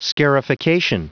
Prononciation du mot : scarification